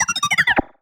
sci-fi_driod_robot_emote_beeps_01.wav